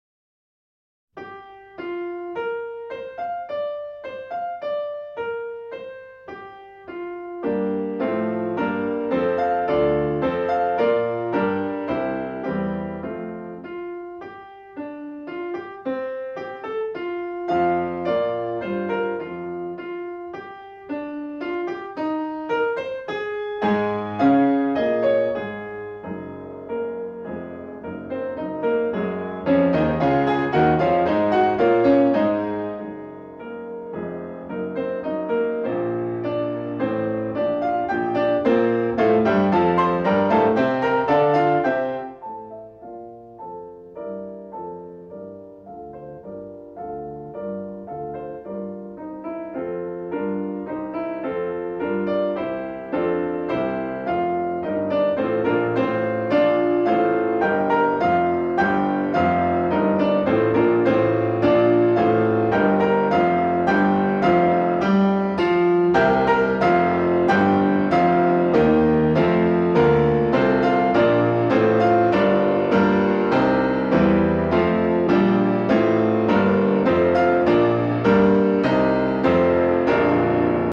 (Когда дети пишут, включить Интермедию "Прогулка" из цикла "Картинки с выставки" в фортепианном изложении.